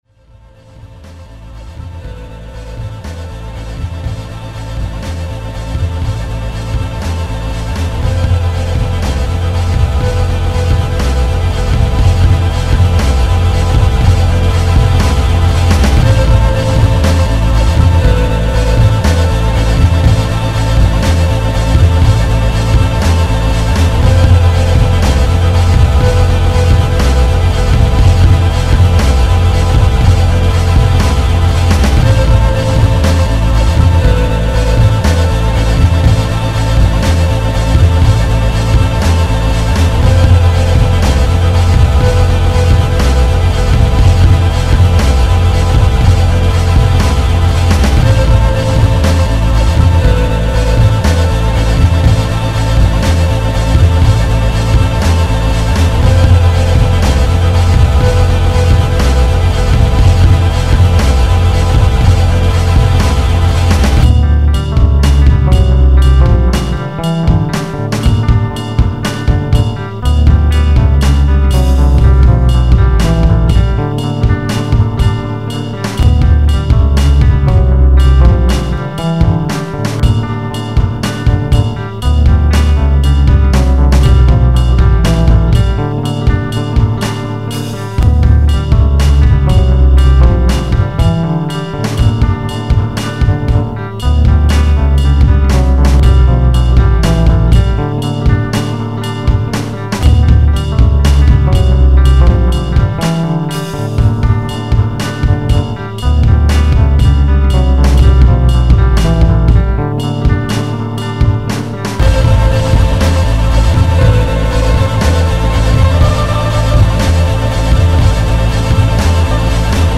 Guitares, samples, claviers, programmations, bricolages.
à évolué vers un son plus électronique